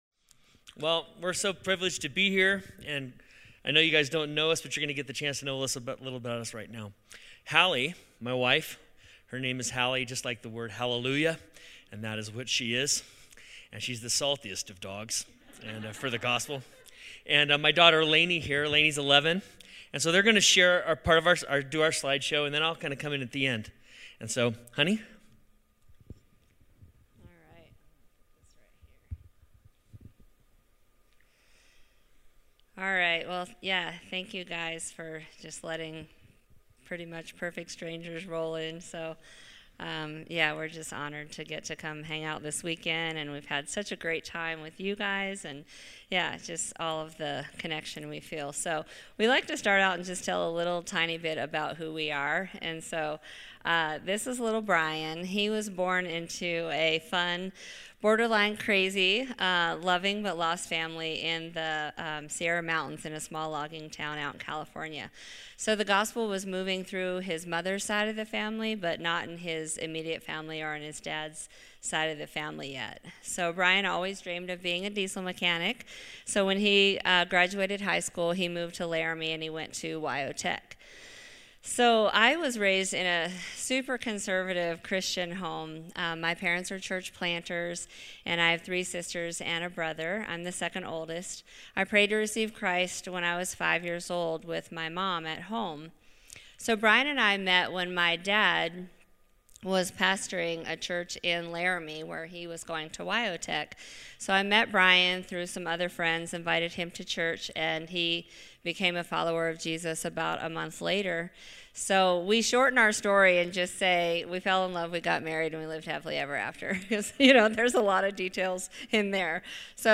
These times are a monthly, co-ed discipleship meeting with worship, teaching, empowering, envisioning and fellowship — all with the leaders and aspiring leaders of our church.